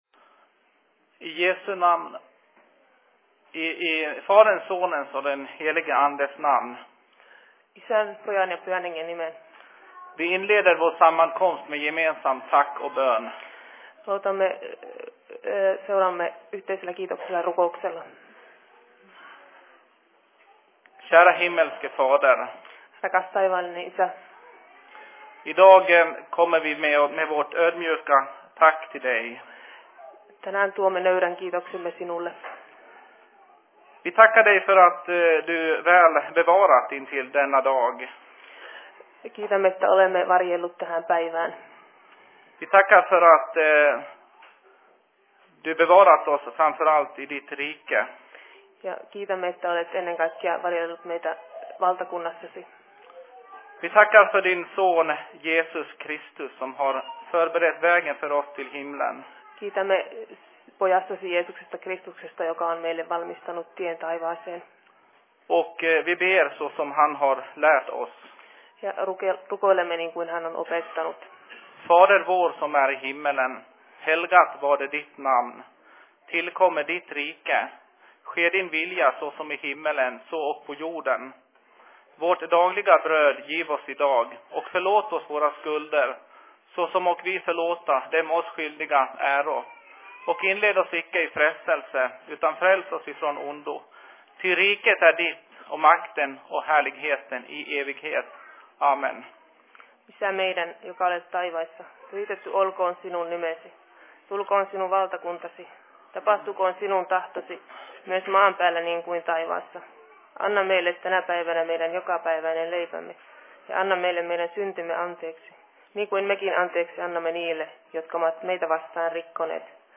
Se Fi Predikan I Dalarnas Fridsförening 09.10.2011
Paikka: SFC Dalarna
Simultaanitulkattu